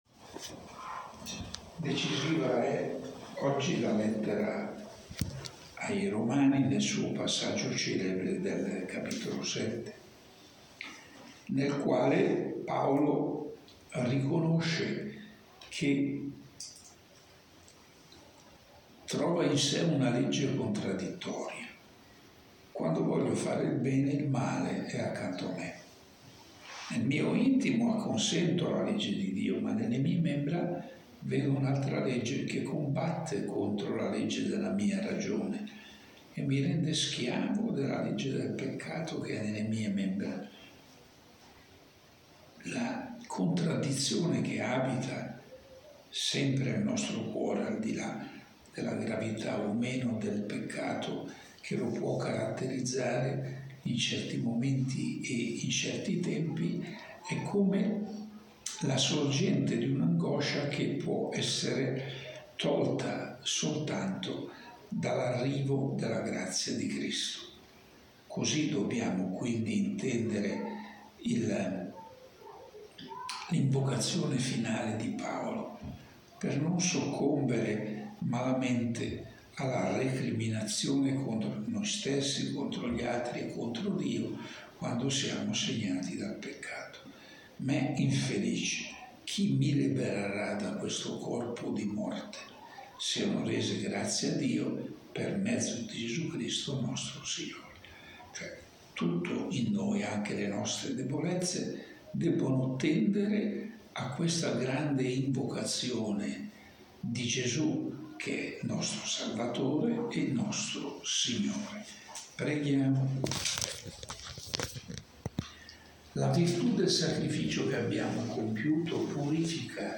Proponiamo la registrazione audio dell’omelia pronunciata dal cardinale Scola domenica 13 febbraio 2022, VI domenica dopo l’Epifania, presso la cappellina della sua abitazione di Imberido.